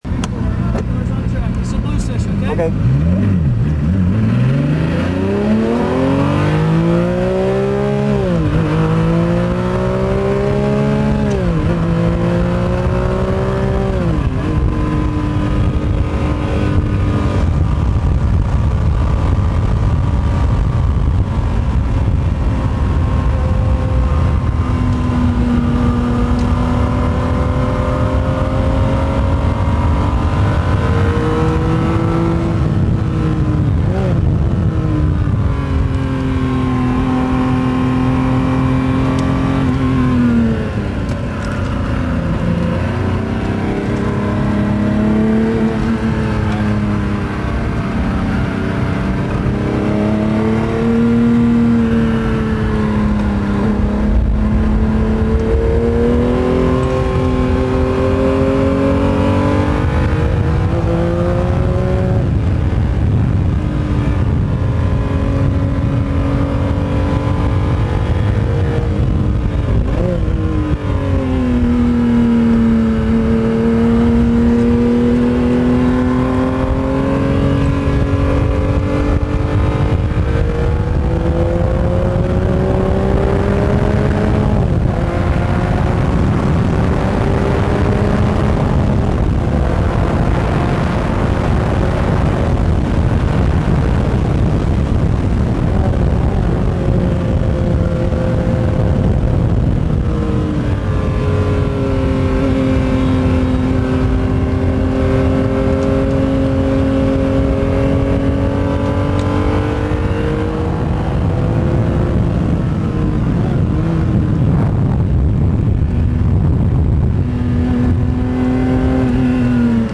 Two Lap F50 (1.7 MB MP3 Format) This is a recording of two laps around Texas World Speedway from the INSIDE of the Ferrari F50. Note: at one point you will hear the exhaust note echo off the wall at over 160MPH. new